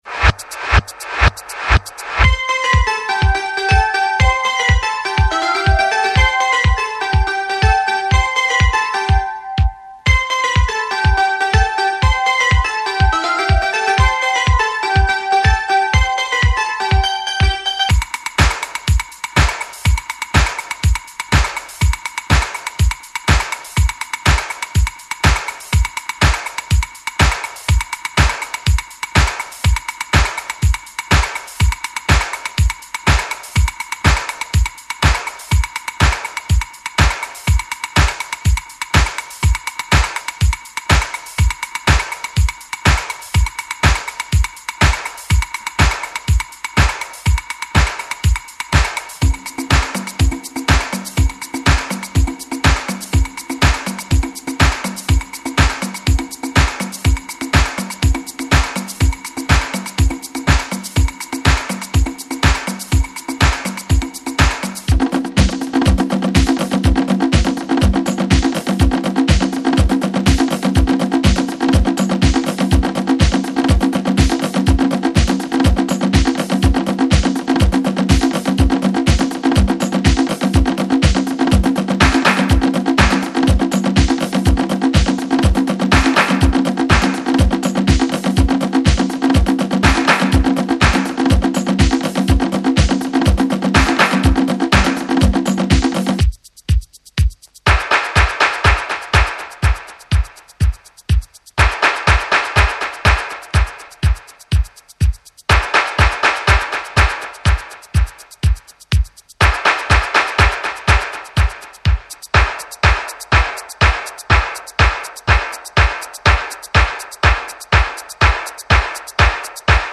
イタロディスコ・クラシック。パーカッションが強烈にいい感じ。